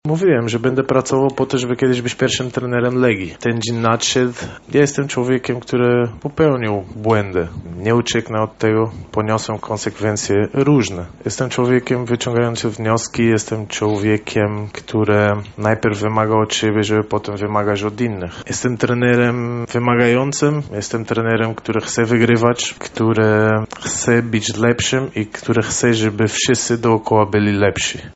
• mówił na konferencji 34-letni portugalski szkoleniowiec.